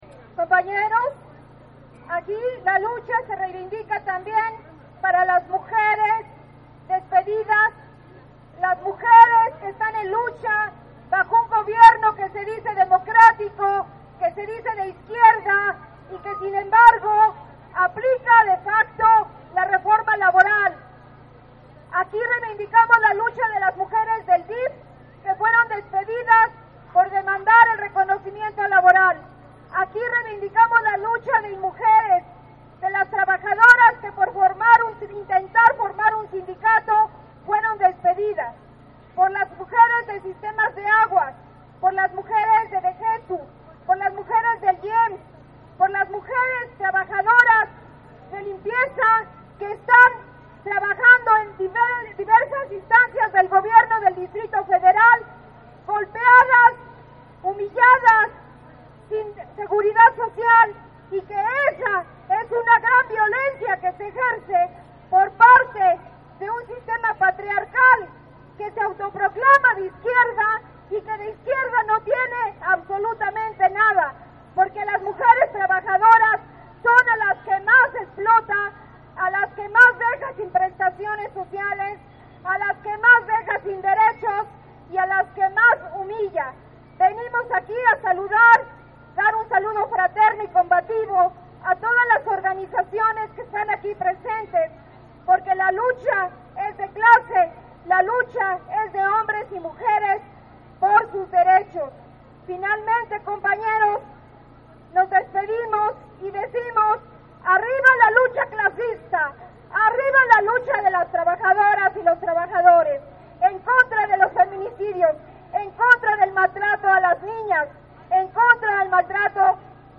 El pasado 25 de noviembre se realizó una marcha por el 25 de noviembre "día internacional contra la violencia a la Mujer"que partió del monumento a la Madre hacia el Palacio de Bellas Artes, la cual inició a medio día, esta fue convocada por colectivos feministas, como Pan y Rosas, Mujeres y la Sexta, entre otras; apróximadamente a las 2 de la tarde arribaron a la av. Juarez donde se llevó acabo la actividad político-cultural.